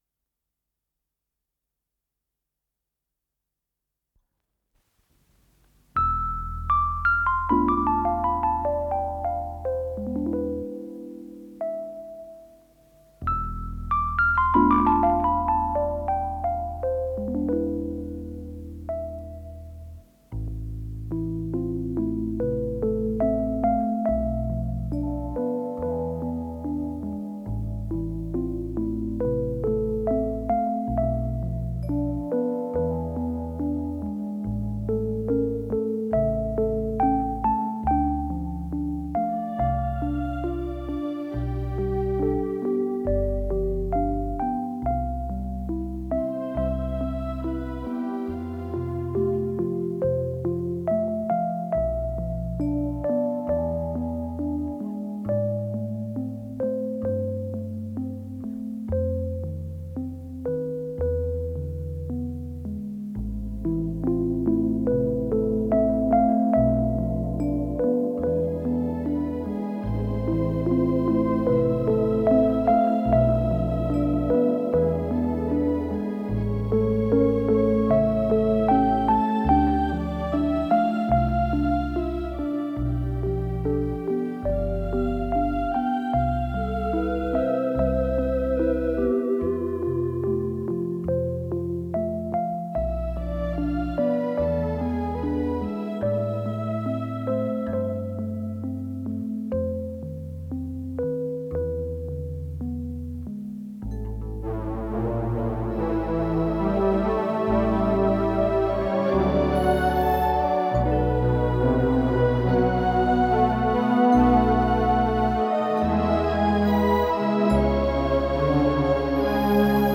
оригинал для эстрадного оркестра»#3676
ВариантДубль моно